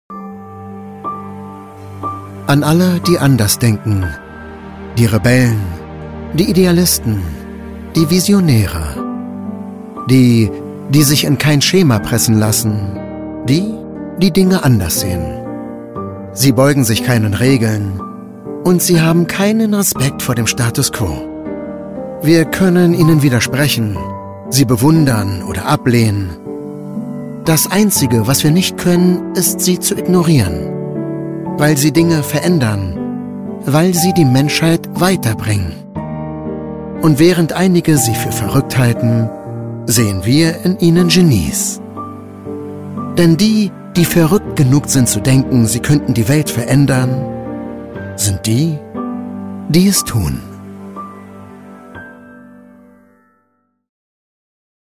markant, sehr variabel
Mittel plus (35-65)
Commercial (Werbung)